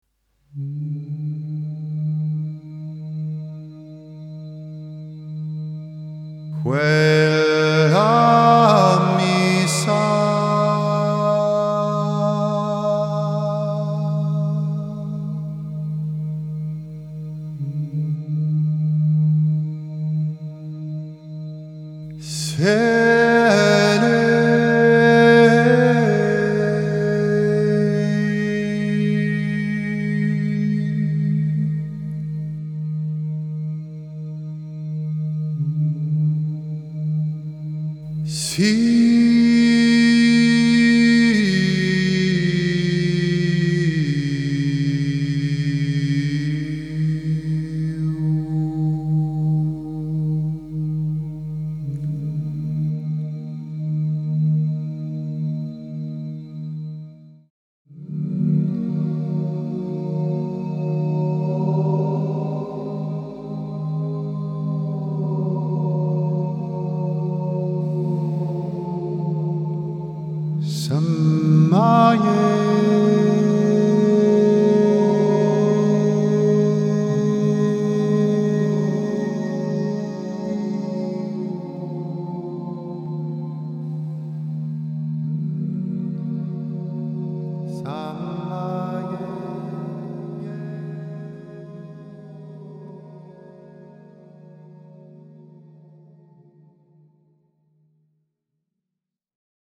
I don't think of any specific words when I sing.